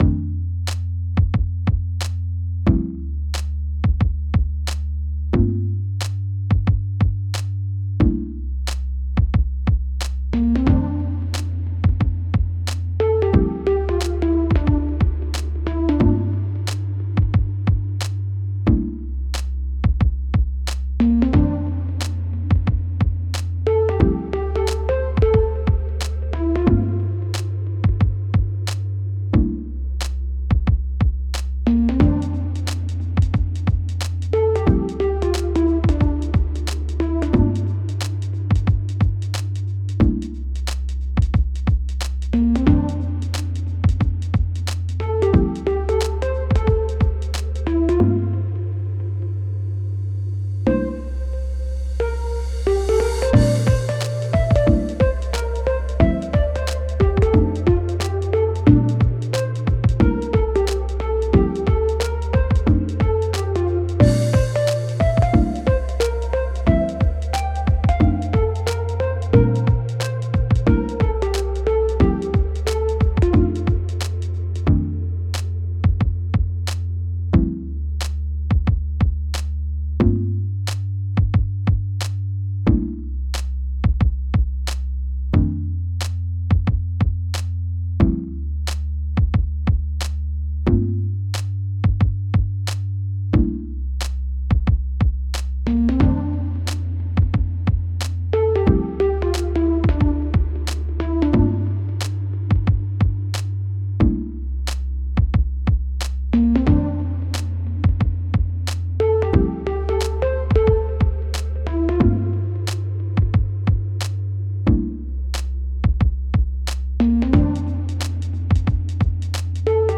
BGM
まるで夢の中にそっと落ちていくような、やさしく静かなBGM。